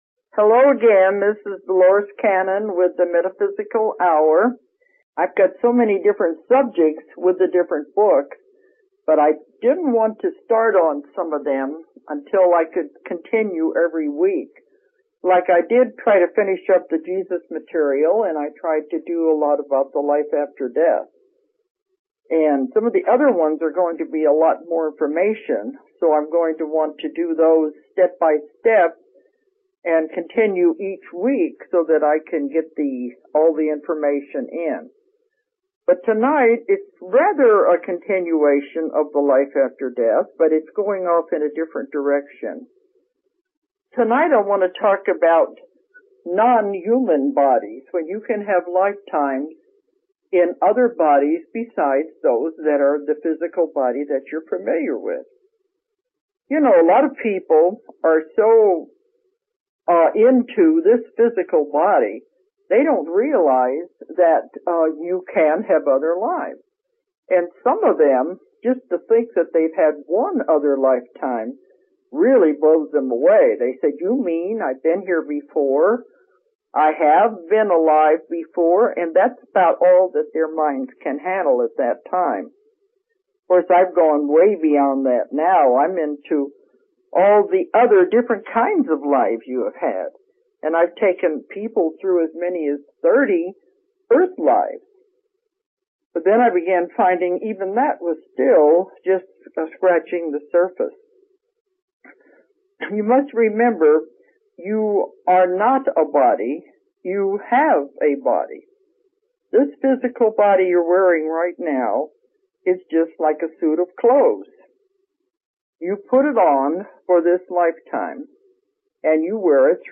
* Notice -- This podcast's audio quality is poor during the second half of the show *